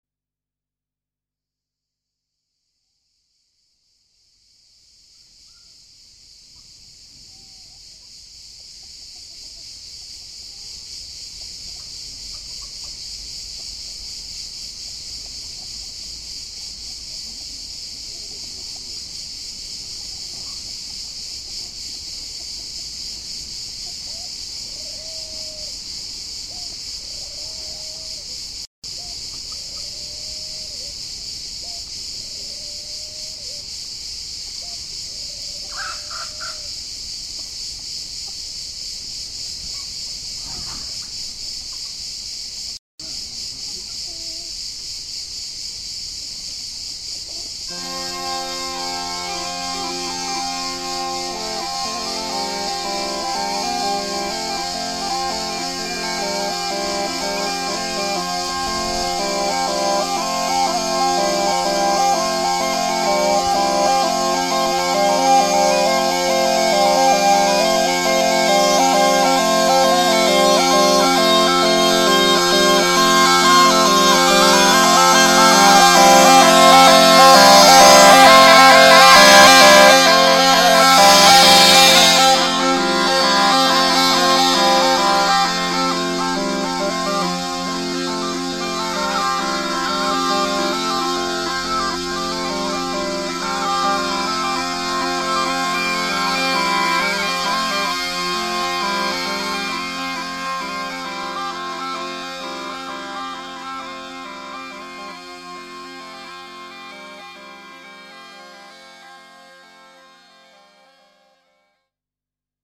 11.the last big piper of my village
the last big piper of my village.mp3